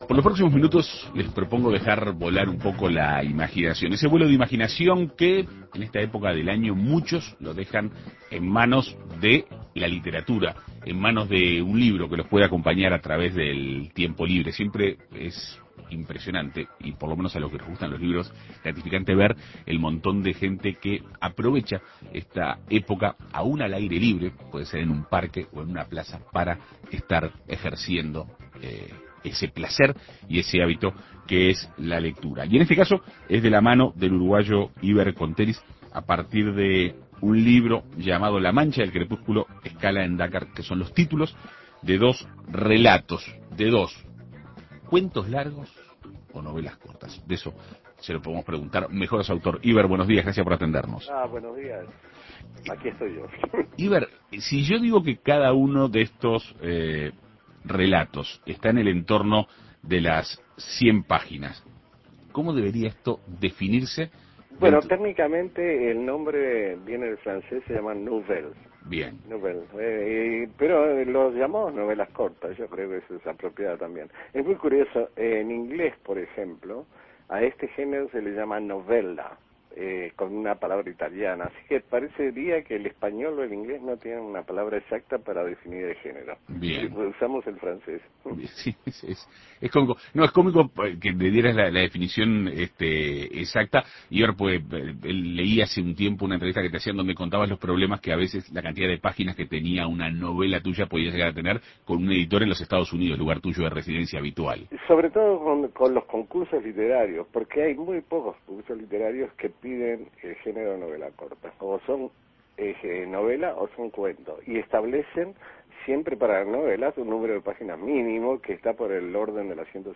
Un libro que nos puede acompañar en los tiempos libres, más aún en verano, pueden ser el que incluye las dos novelas cortas realizadas por Hiber Conteris durante su reclusión como preso político. En Perspectiva Segunda Mañana dialogó con el autor para conocer más sobre la obra.